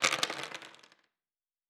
Dice Multiple 9.wav